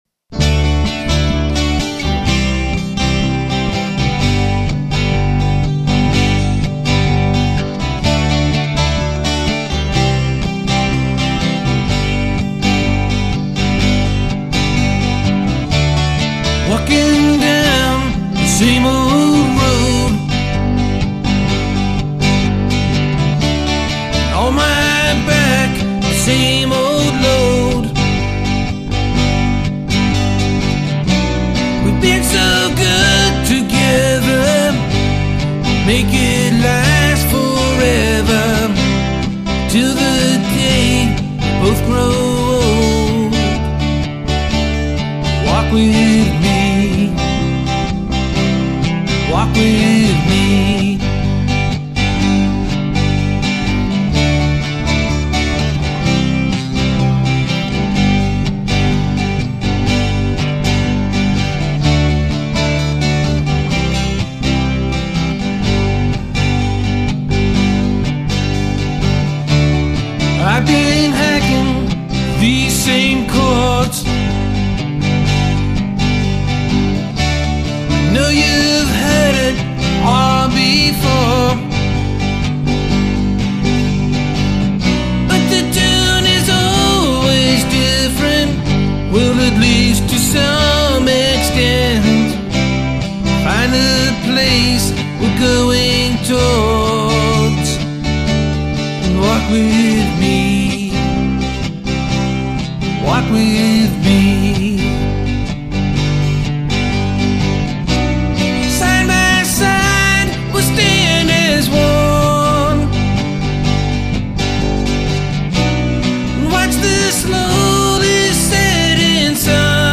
An Acoustic version of the song. 12